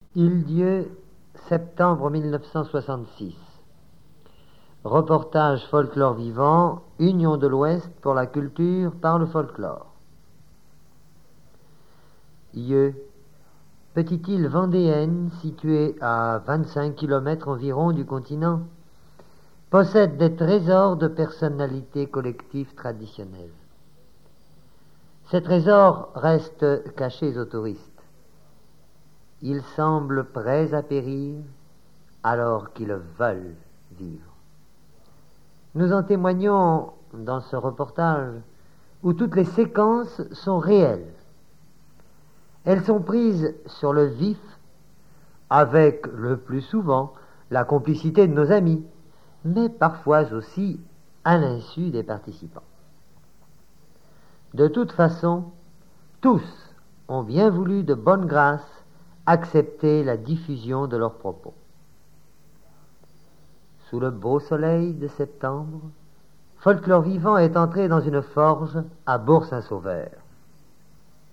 Reportage Folklore vivant
reportage et montage sonore relatif à Saint-Sauveur